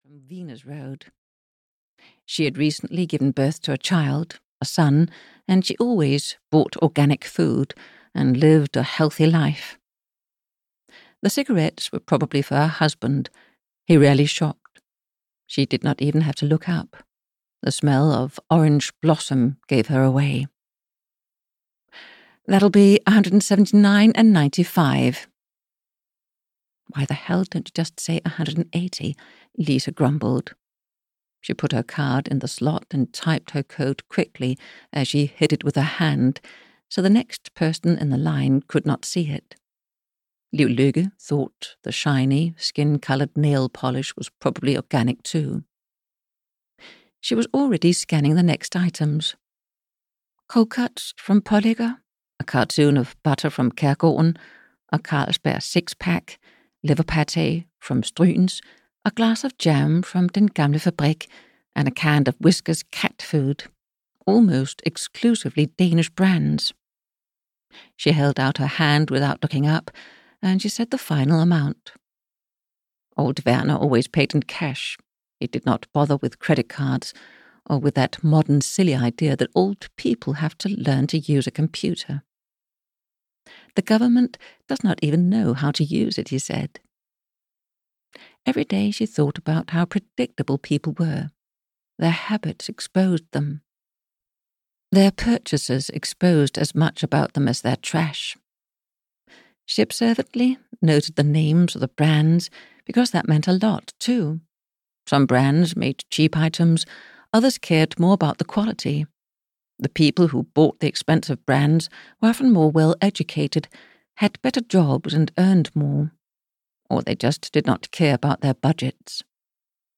Burning Guilt - Chapter 2 (EN) audiokniha
Audiobook Burning guilt, written by Inger Gammelgaard Madsen.
Ukázka z knihy